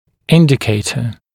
[‘ɪndɪkeɪtə][‘индикейтэ]индикатор, признак, показатель, указатель